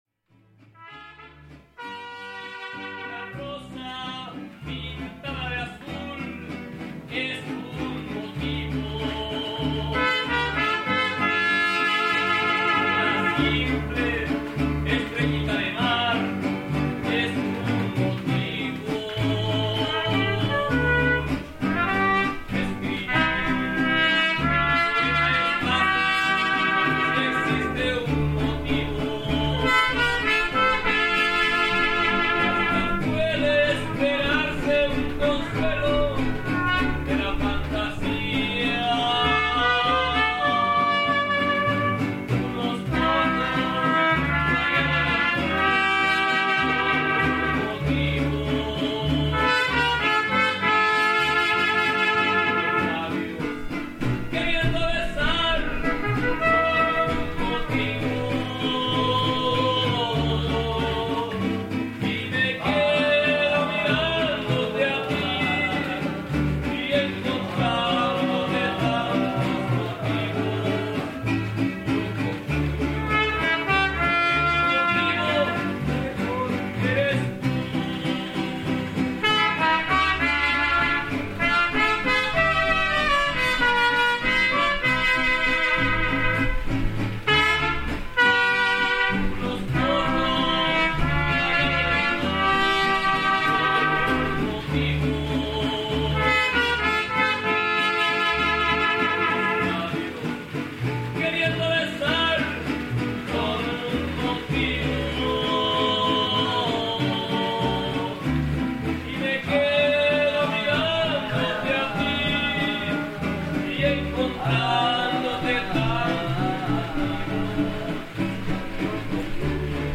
Serenata en la lluvia
Lugar: Tuxtla Gutierrez, Chiapas; Mexico.
Hora: 02:33 horas. Equipo: Grabadora Sony ICD-UX80 Stereo